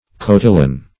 Cotillon \Co`til`lon"\ (k[-o]`t[-e]`y[^o]N" or k[-o]`t[-e]l`-;
cotillon.mp3